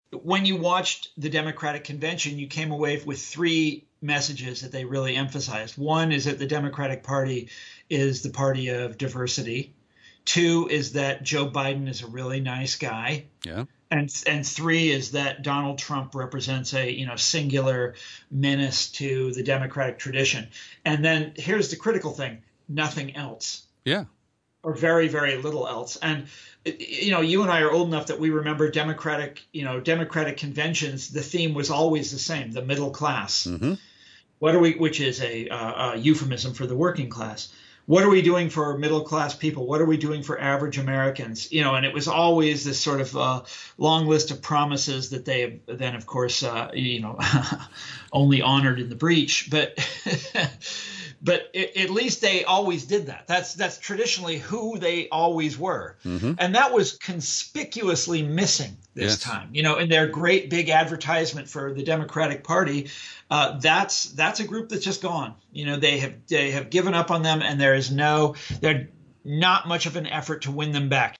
In-Depth Interview: Author and Historian Thomas Frank Comments on DNC/RNC Conventions and Populism